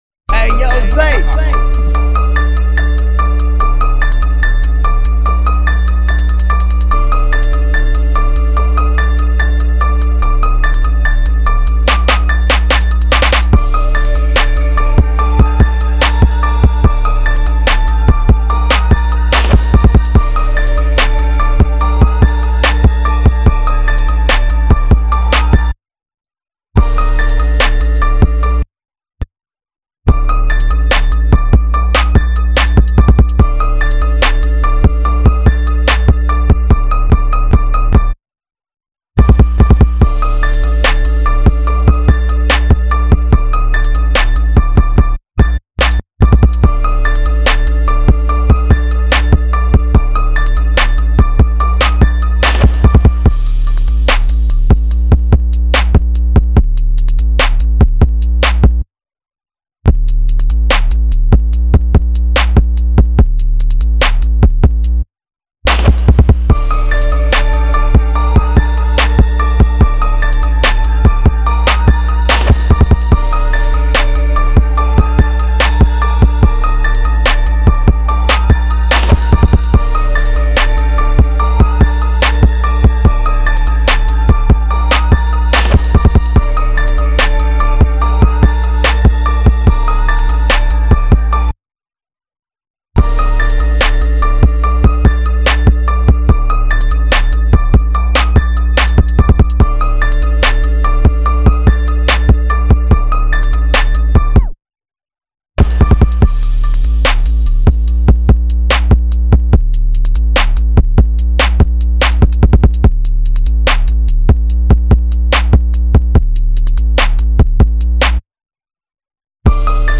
official instrumental
Rap Instrumental